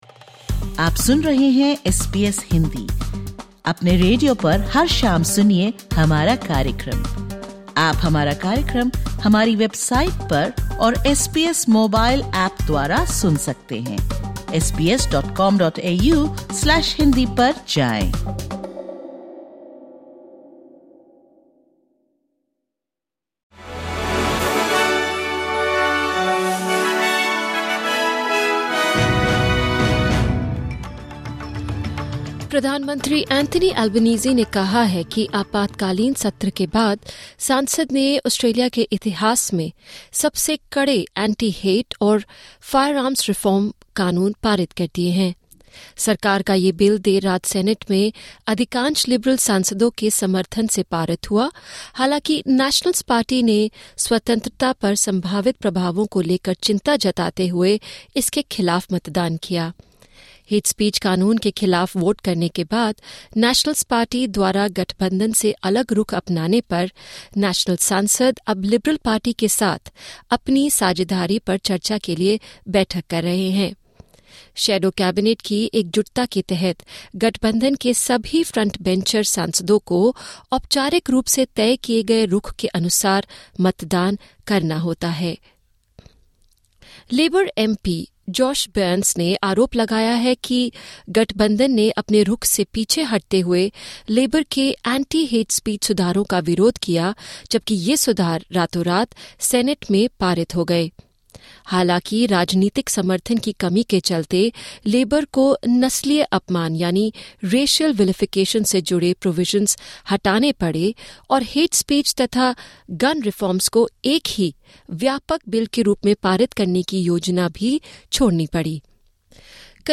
Listen to the top News of 21/01/2026 from Australia in Hindi.